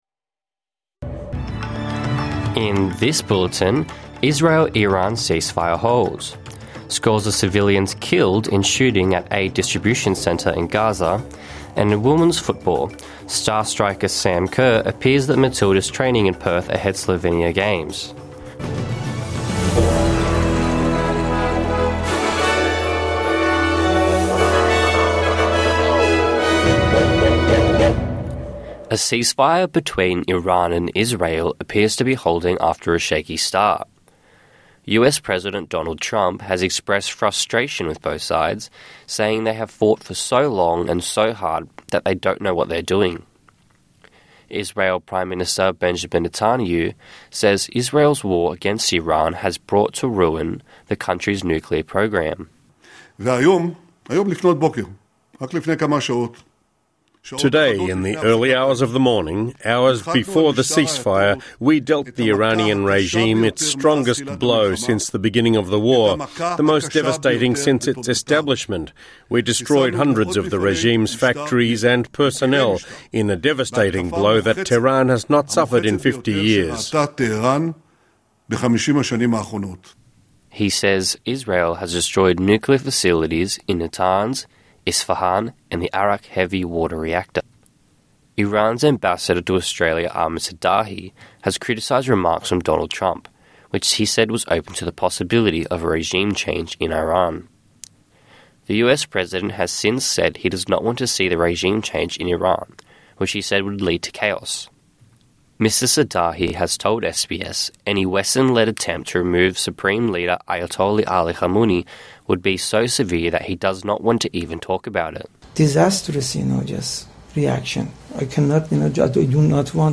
NITV Radio news, sport and weather. Palestinian witnesses and health officials say Israeli forces have repeatedly opened fire on crowds heading to sites for food. Sam Kerr appears at Matilda's training in Perth ahead of Slovenian games.